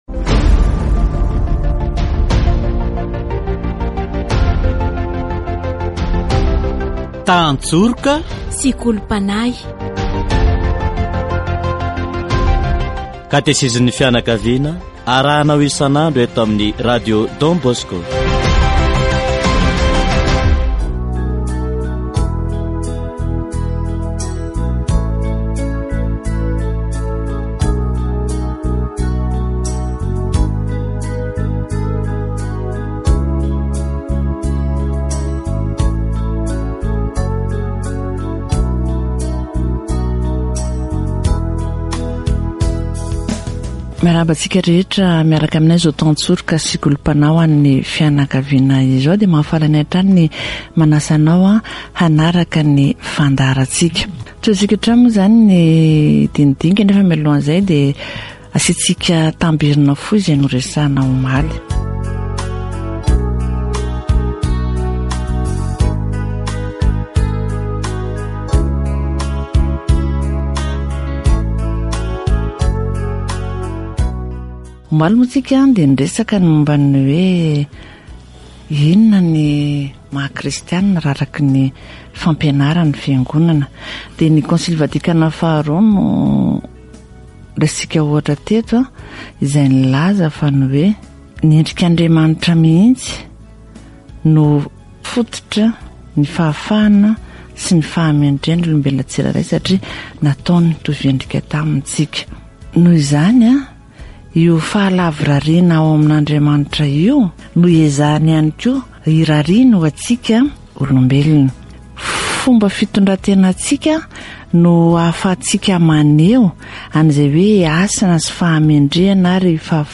Catégorie : Approfondissement de la foi